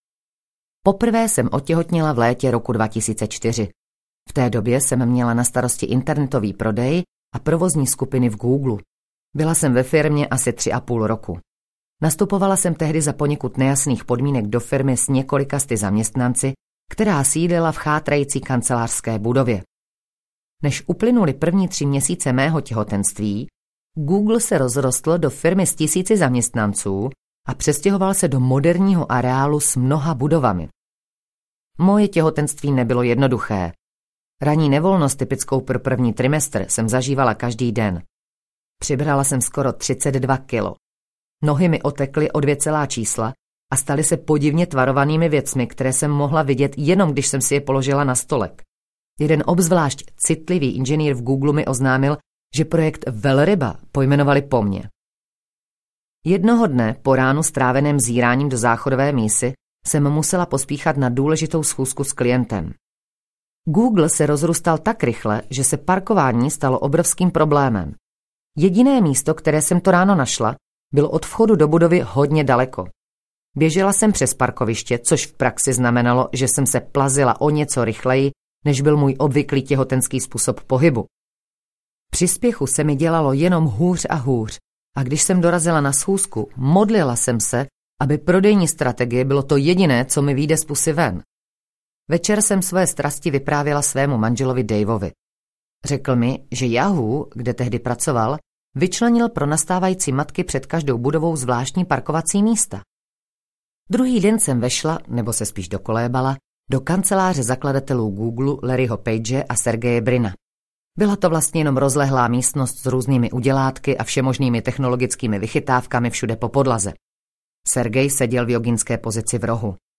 Opřete se do TOHO audiokniha
Ukázka z knihy
• InterpretSimona Babčáková